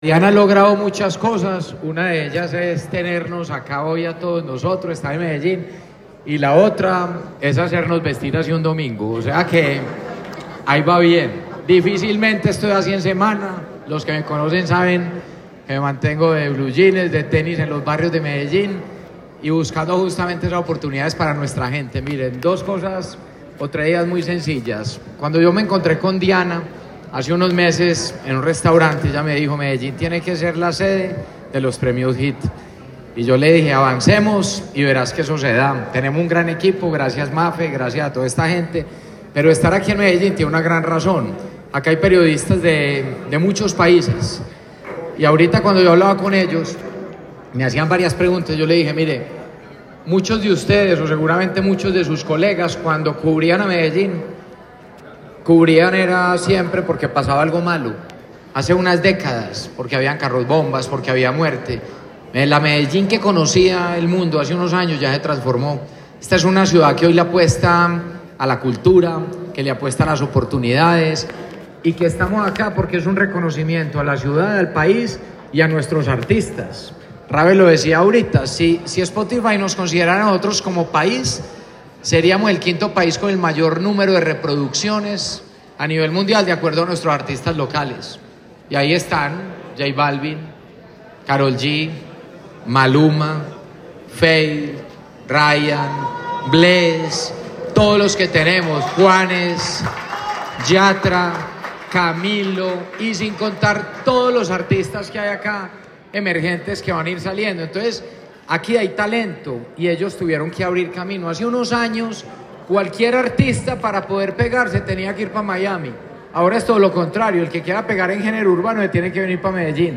Audio Declaraciones del alcalde de Medellín, Federico Gutiérrez Zuluaga
Audio-Declaraciones-del-alcalde-de-Medellin-Federico-Gutierrez-Zuluaga-01.mp3